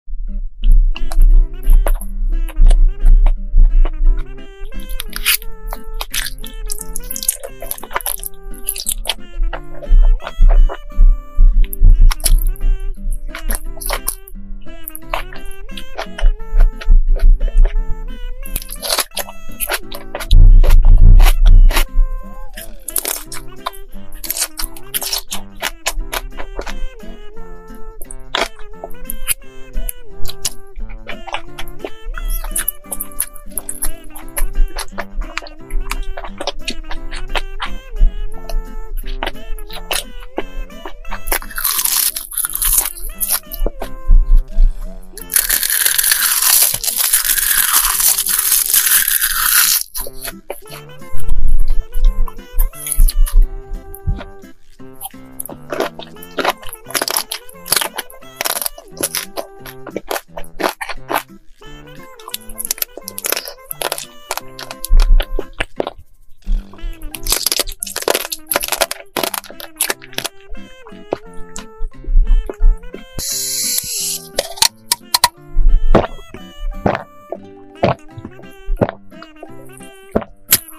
ASMR EMOJI FOOD kohakuto sprinkle sound effects free download
ASMR EMOJI FOOD kohakuto sprinkle honey jelly sea grape ice chocolate mukbang eating sounds